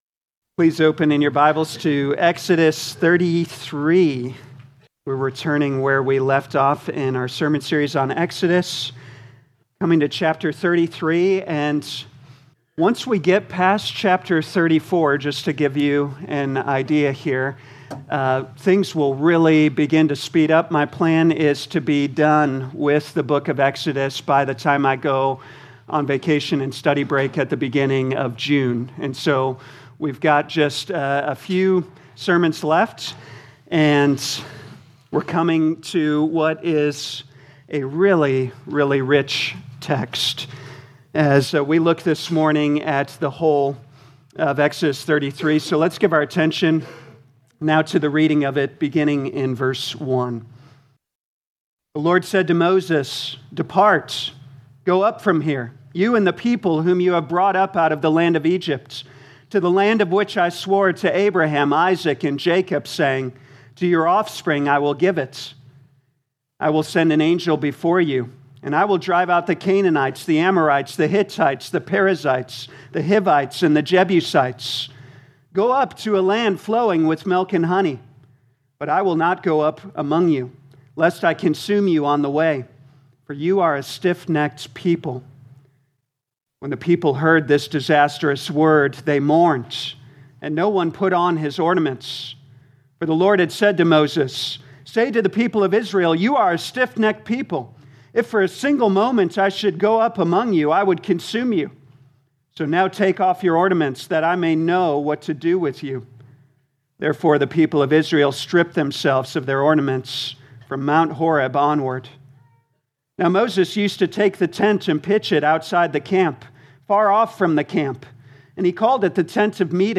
2026 Exodus Morning Service Download